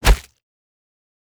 Punch Impact (Flesh) 3.wav